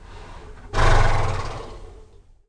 BlusterA22.wav